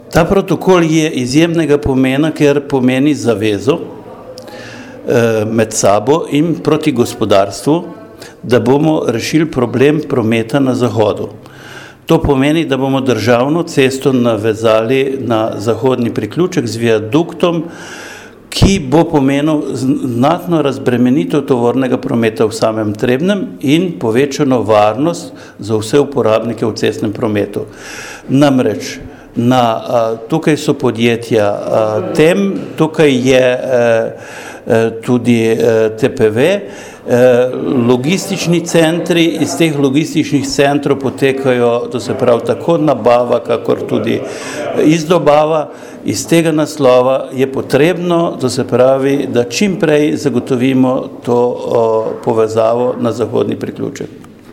izjava_alojzija_kastelica_zupana_obcine_trebnje_29_9_2017.mp3 (964kB)